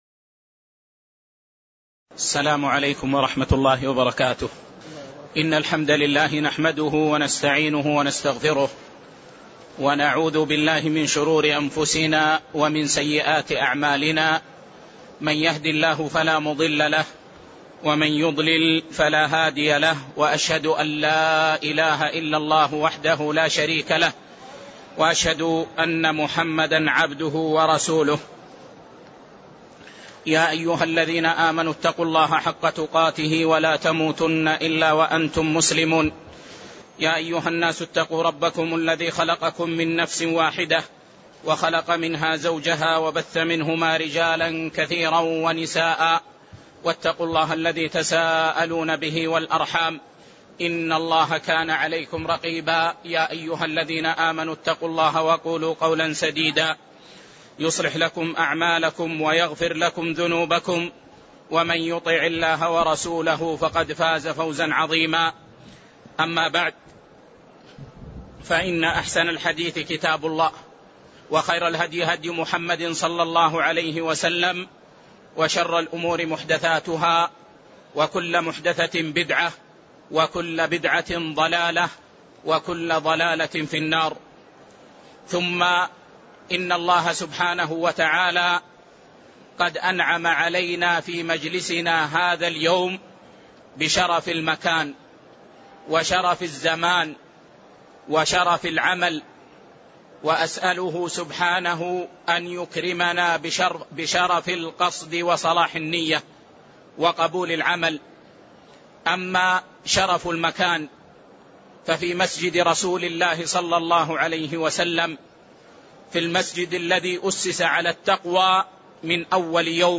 تاريخ النشر ٢١ ذو القعدة ١٤٣٤ هـ المكان: المسجد النبوي الشيخ